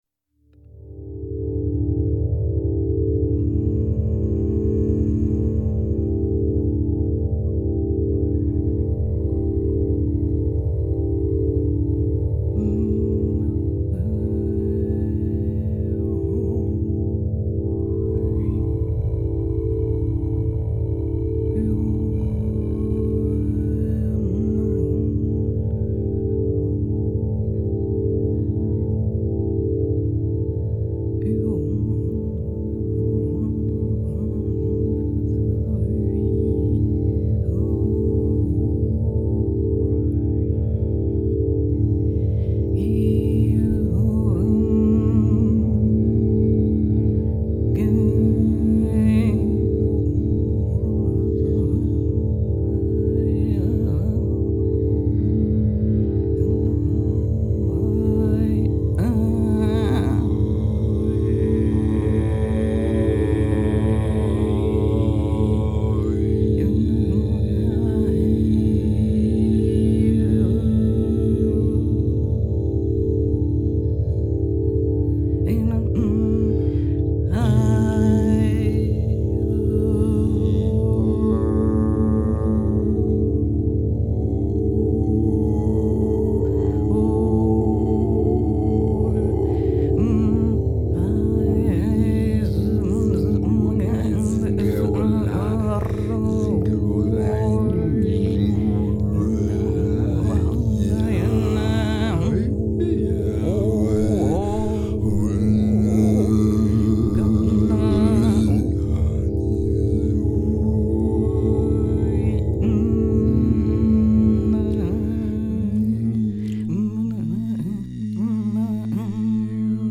two voices and tape
premiered september 2006, concert